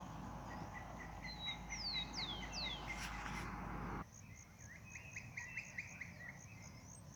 Scientific name: Colaptes melanochloros melanolaimus
English Name: Green-barred Woodpecker
Sex: Both
Life Stage: Adult
Location or protected area: Concordia
Condition: Wild
Certainty: Observed, Recorded vocal